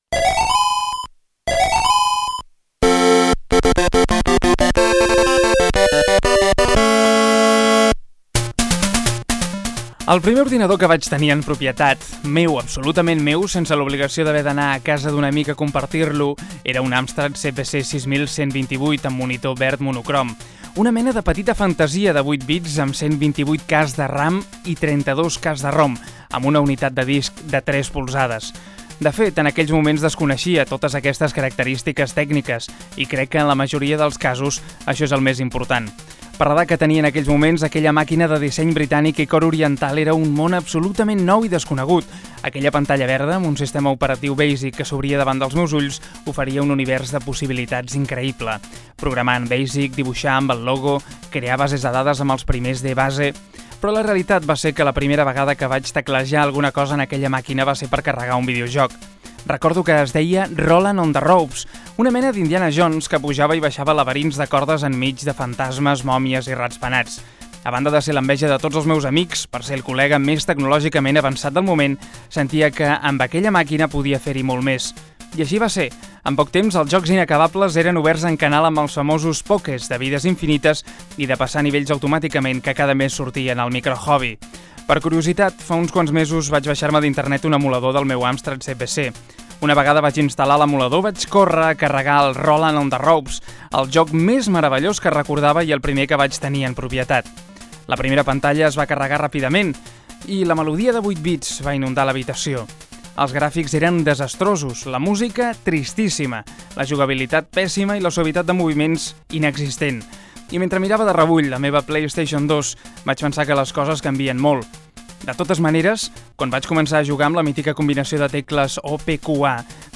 El meu primer ordinador (un Amstrad) i el meu primer vídeojoc, indicatiu del programa, sumari de continguts, indicatiu, reportatge "Game classic" sobre el "Pacman".
Divulgació
FM